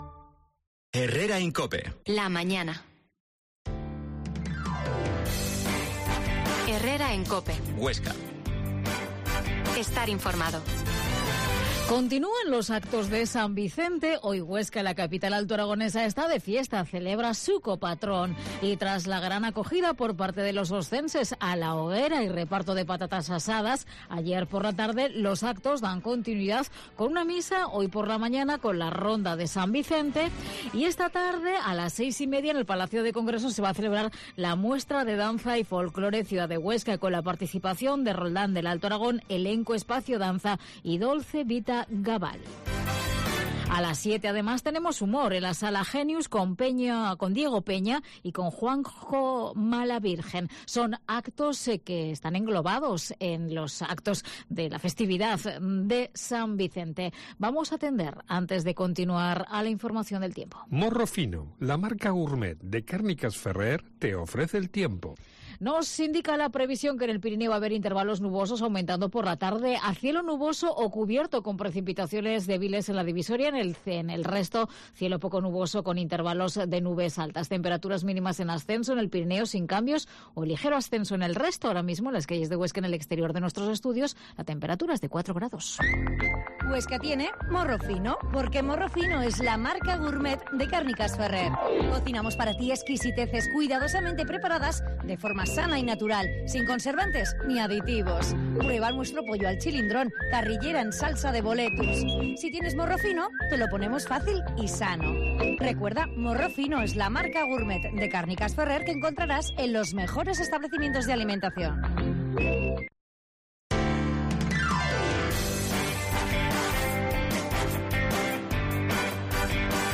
La Mañana en COPE Huesca - Informativo local Mediodía en Cope Huesca 13,50h Actualidad local.